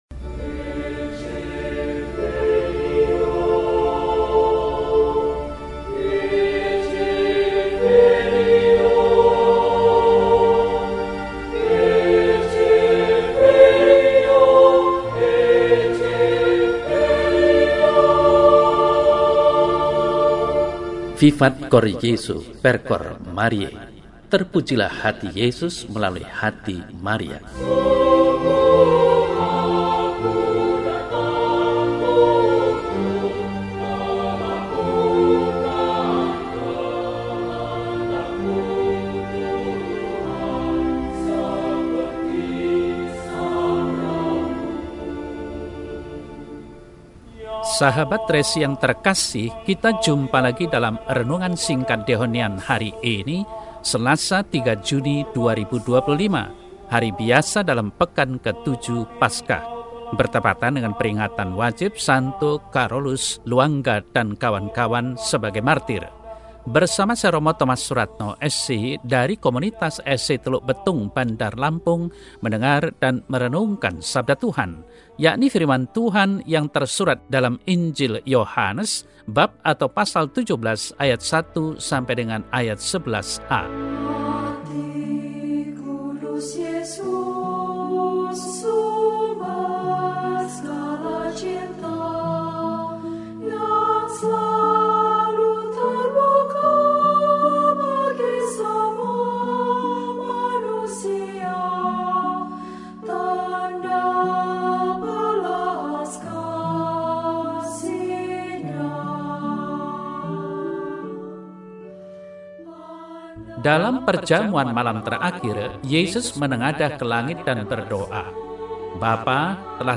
Selasa, 03 Juni 2025 – Peringatan Wajib St. Karolus Lwanga, dkk Martir (Novena Roh Kudus hari kelima) – RESI (Renungan Singkat) DEHONIAN